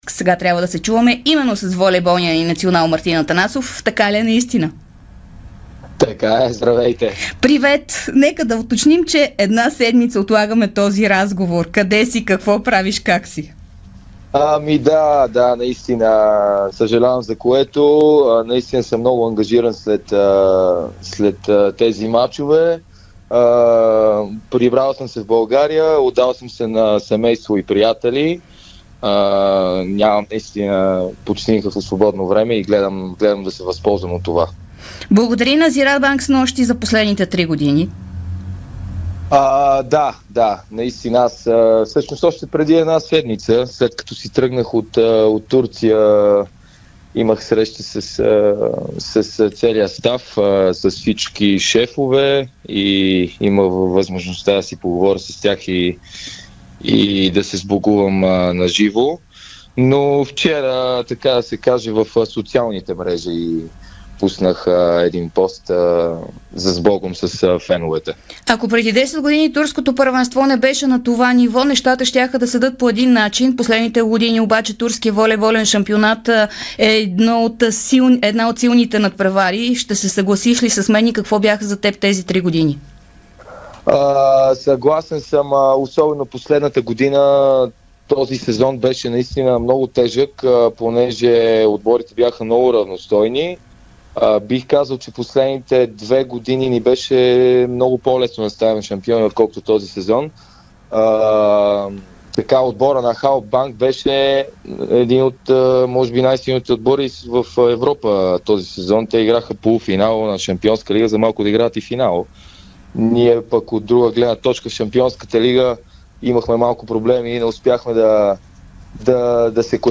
Волейболният национал на България Мартин Атанасов даде специално интервю пред Дарик радио и dsport.